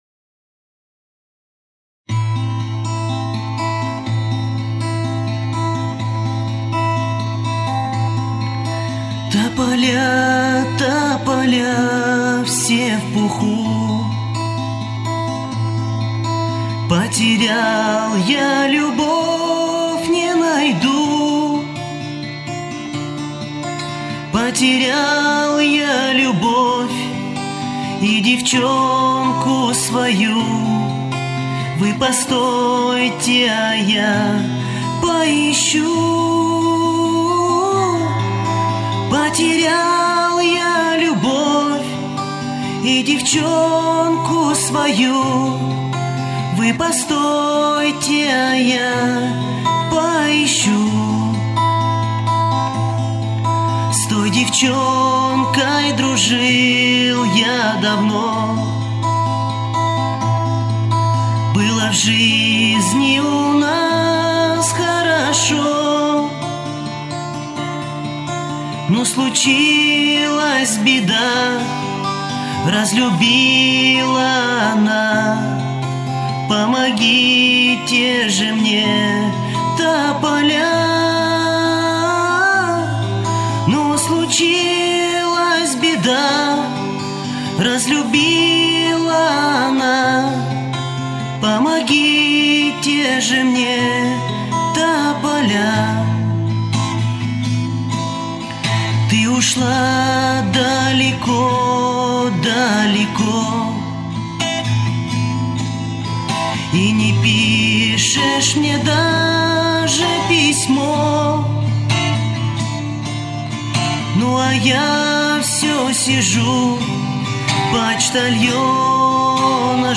armeyskie_pesni_pod_gita_topolya.mp3